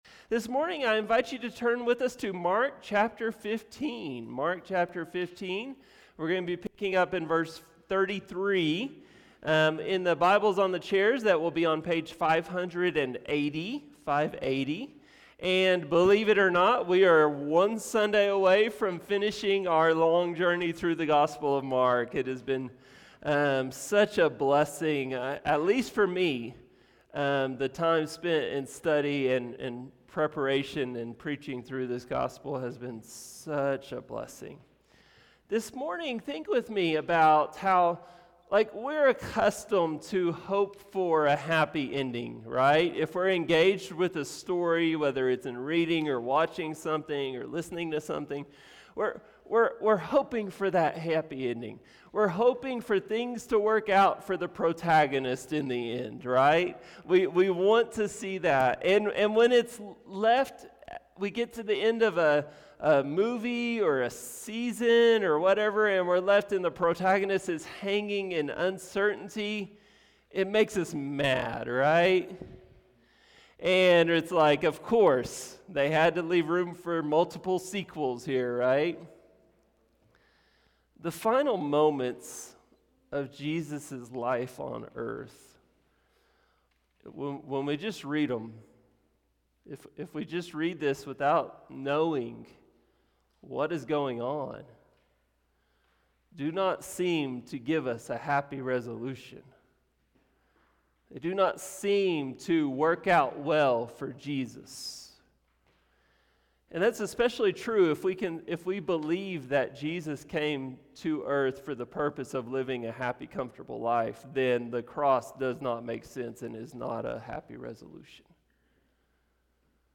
A message from the series "The Gospel of Mark."